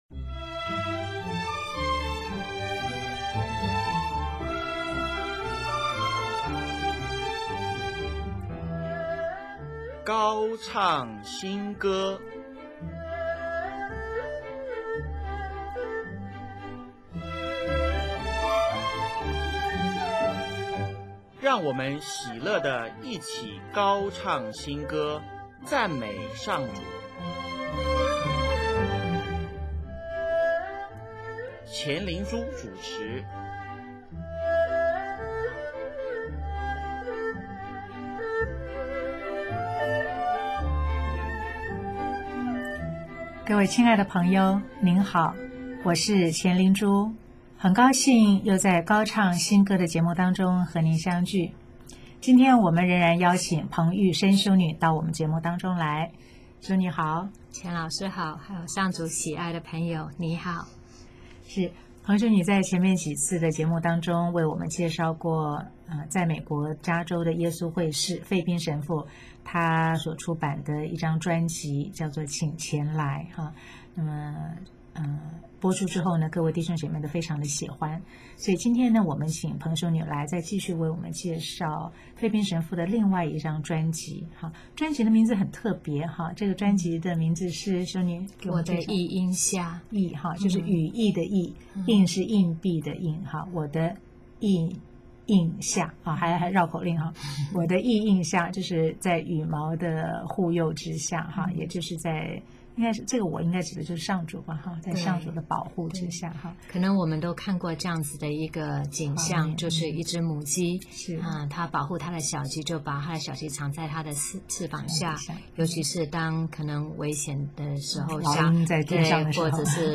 “圣神！请来”，前奏优美，好像圣神真的从天降下，进入我们心灵。
“向上主欢唱”，节奏比较快，歌词选自圣咏第九十八篇。